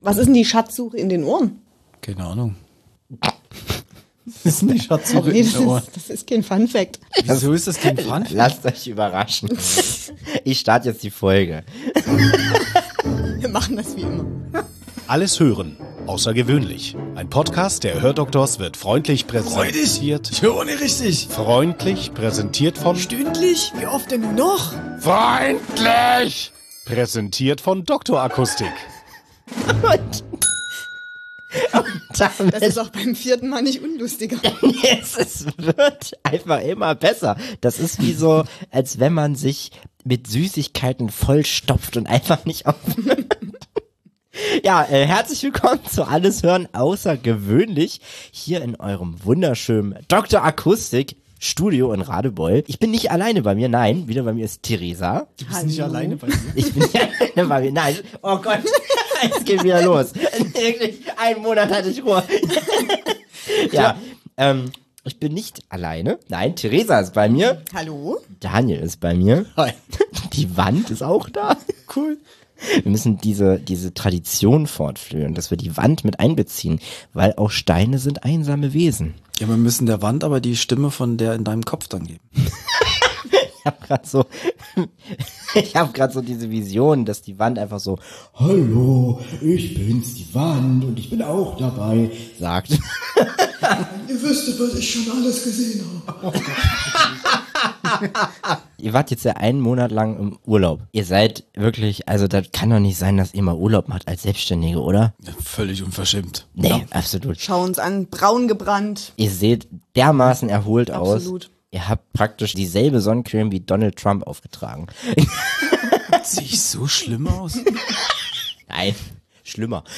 Dieses Mal dreht sich alles um das Rätsel von ASMR – und warum wir es (nicht) klären konnten. Seid gespannt auf viel Schmatzen und interessante Einblicke.